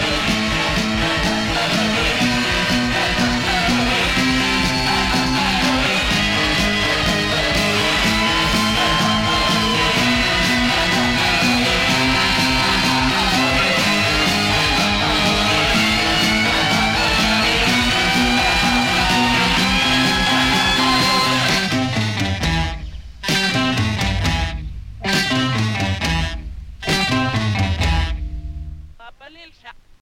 saxofone
Som claramente - diríamos até orgulhosamente - datado